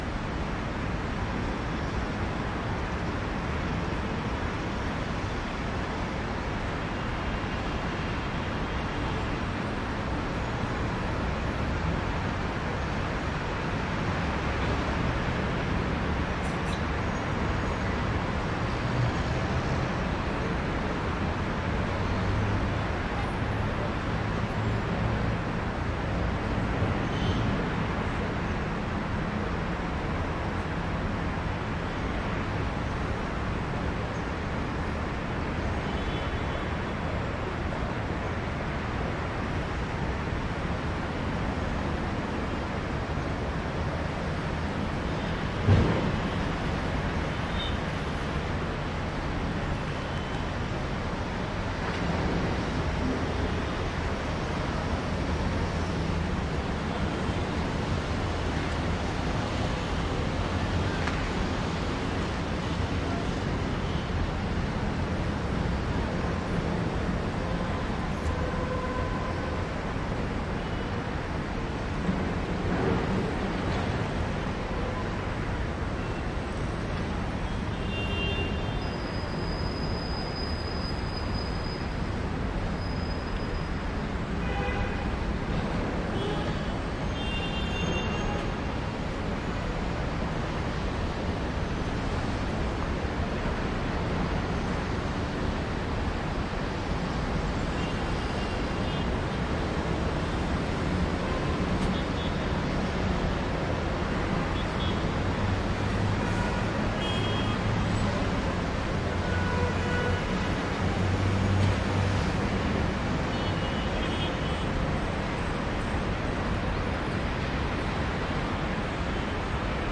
摩洛哥 " 街头咖啡馆 ext walla arabic 与附近的交通雾霾 mopeds 喇叭 卡萨布兰卡，摩洛哥 MS - 声音 - 淘声网 - 免费音效素材资源|视频游戏配乐下载
街头咖啡馆分支walla阿拉伯语与附近交通阴霾轻便摩托车角卡萨布兰卡，摩洛哥MS.wav